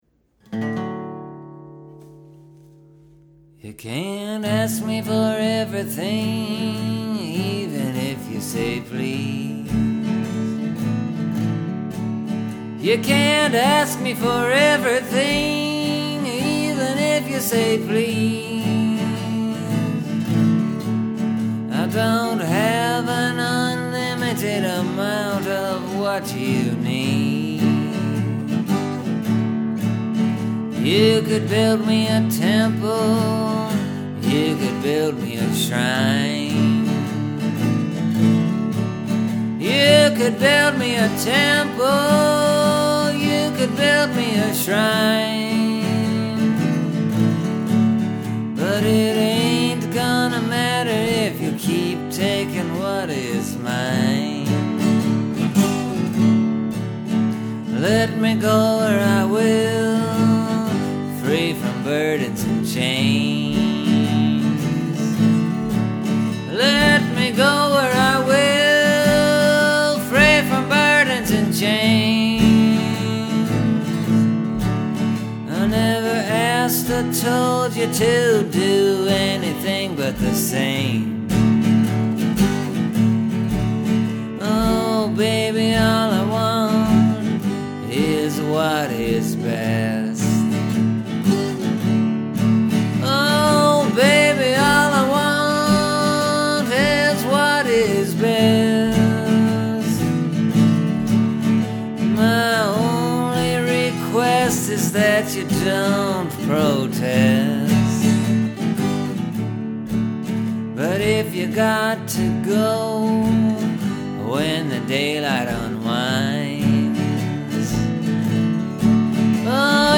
It’s a pretty basic blues structure, expect that there’s a two-chord in there where the five-chord usually would be.